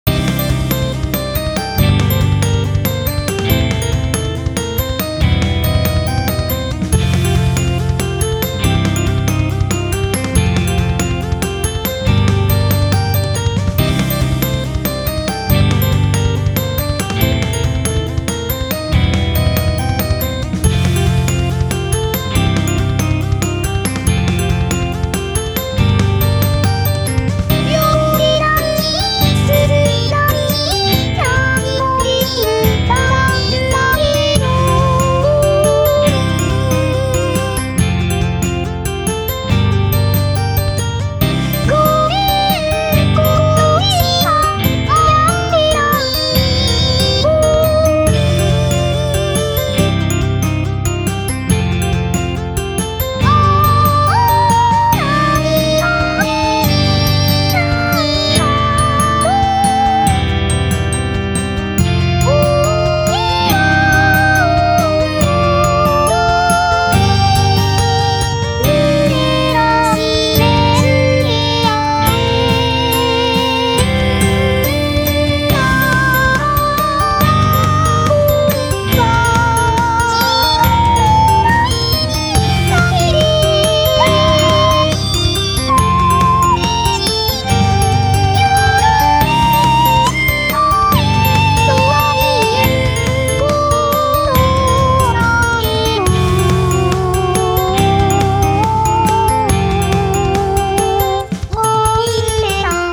歌:重音テト